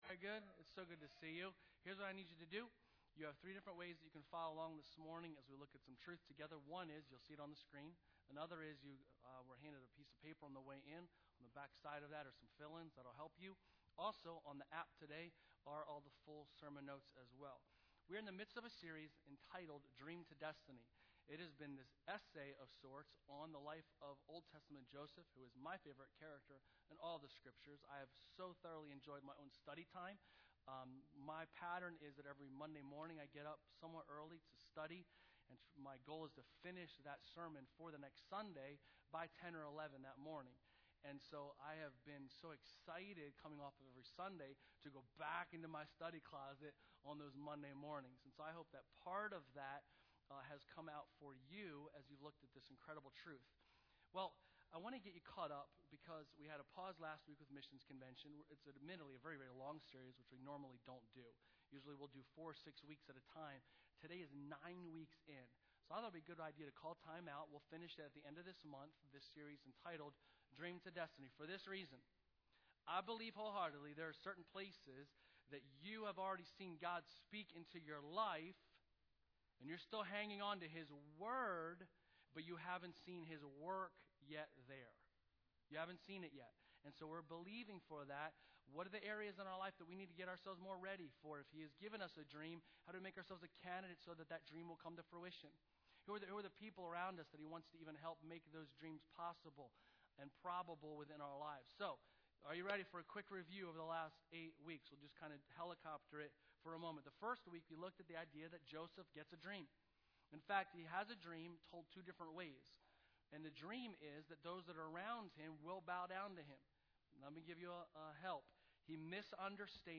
Sermons | Bethany Church